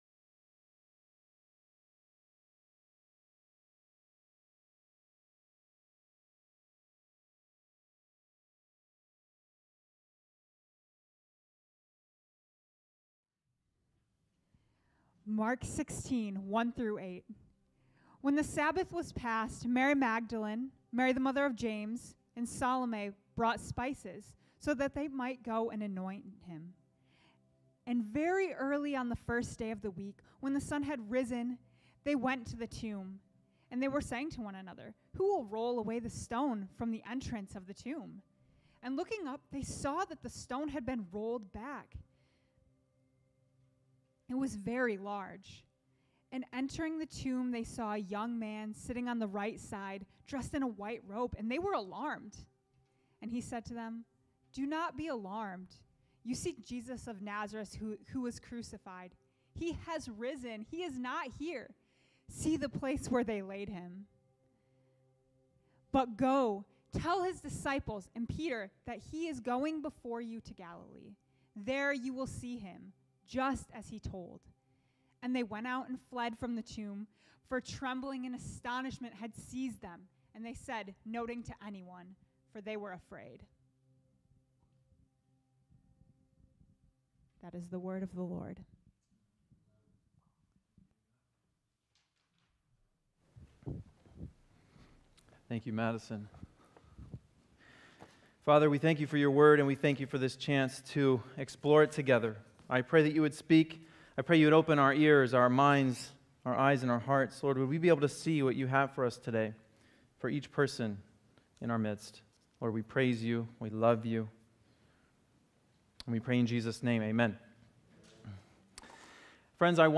Download Download From this series Current Sermon "Jesus Is Alive."